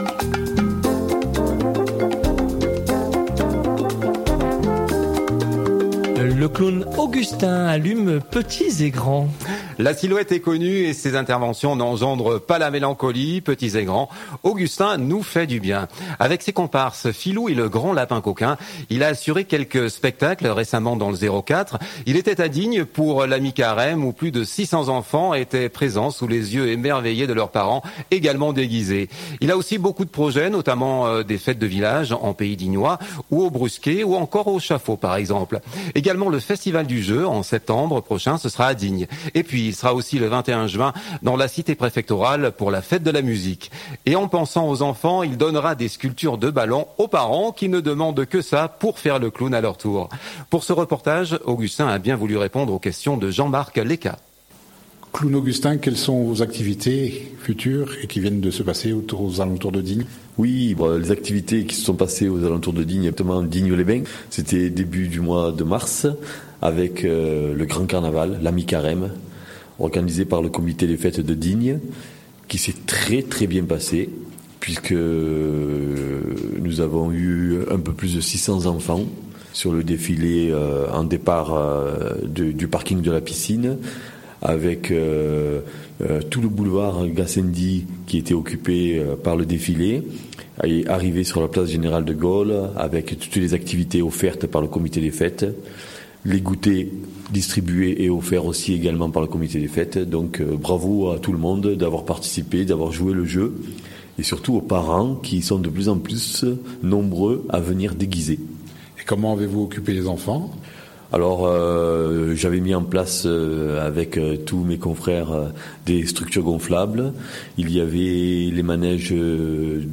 Pour ce reportage